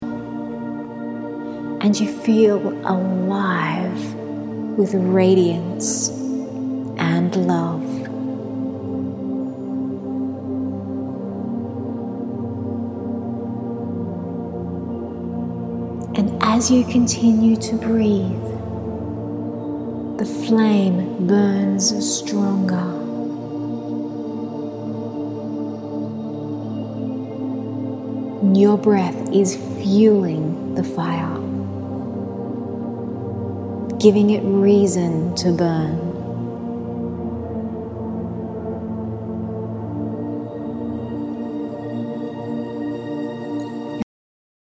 A guided meditation designed to ignite your inner power.
This track is set to delta tones designed to relax your mind into a meditative state.
All guided meditations are recorded with delta tones in the background, when listened to with earplugs in each ear, the recording will send out delta brainwaves that will drop you into a deeper meditative state of consciousness.